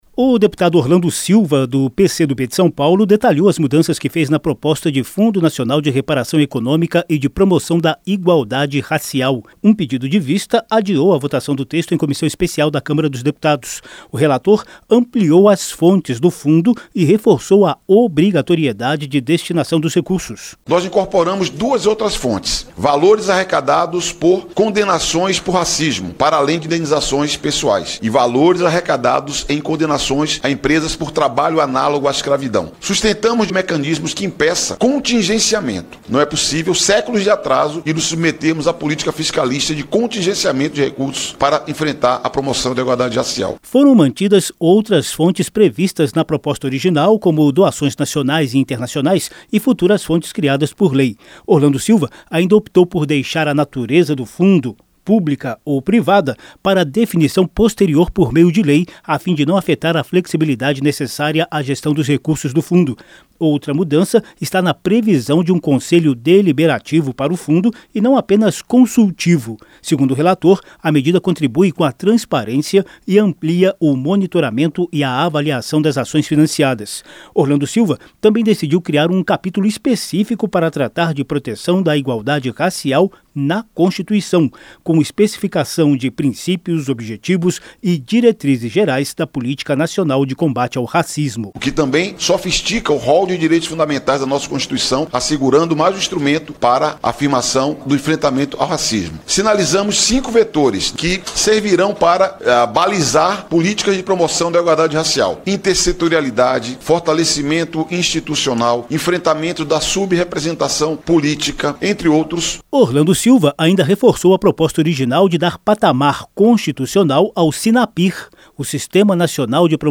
RELATOR DETALHA MUDANÇAS EM PROPOSTA DE FUNDO NACIONAL DA IGUALDADE RACIAL. O REPÓRTER